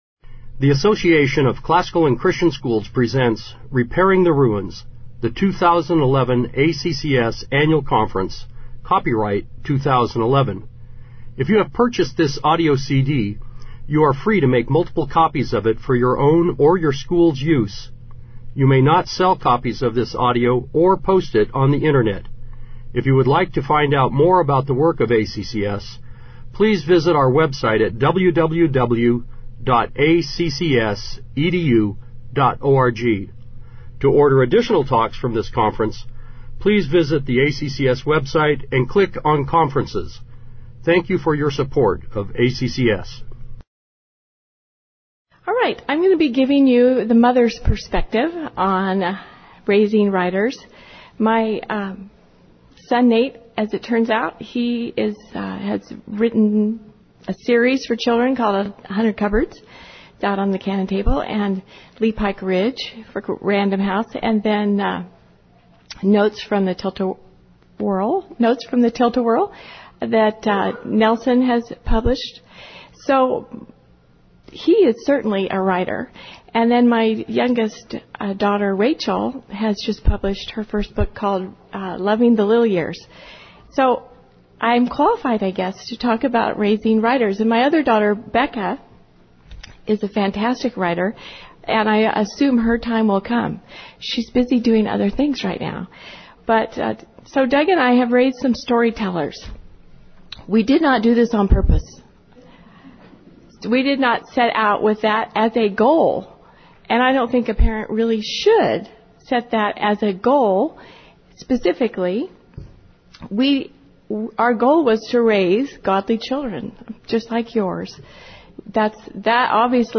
2011 Workshop Talk | 0:44:07 | K-6, Rhetoric & Composition
This workshop will focus on what a mother can do to train up writers. Speaker Additional Materials The Association of Classical & Christian Schools presents Repairing the Ruins, the ACCS annual conference, copyright ACCS.